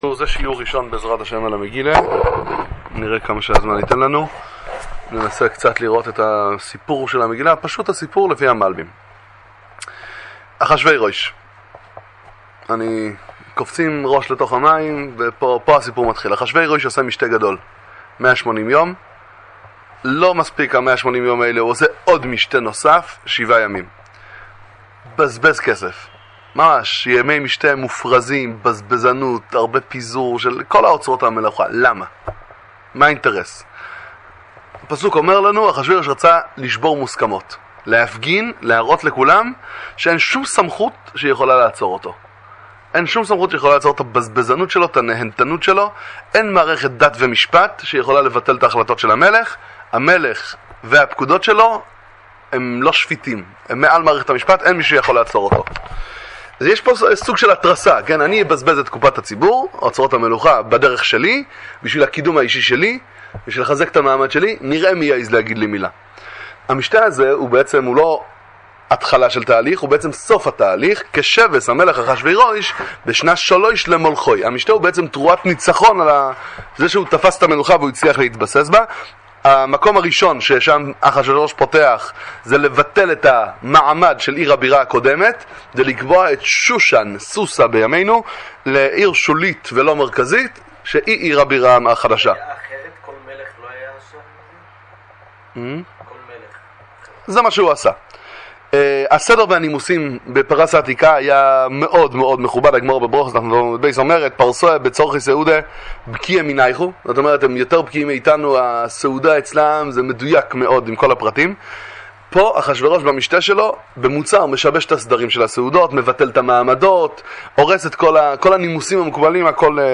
שיעורי תורה על פרשת השבוע ומגילת אסתר לפי פירוש המלבי"ם, ביאור קל ונחמד
שיעור-1-אשנב-למגילה-על-פי-המלבים.mp3